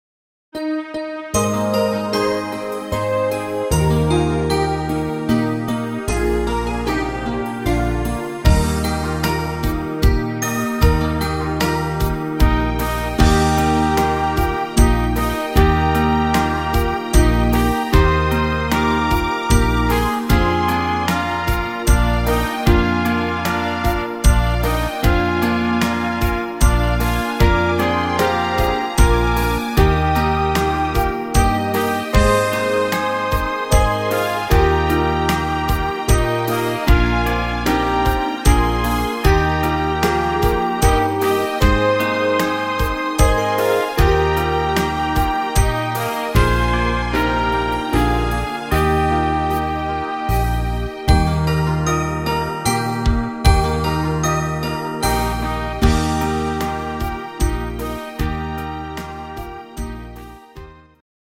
instr. Trompete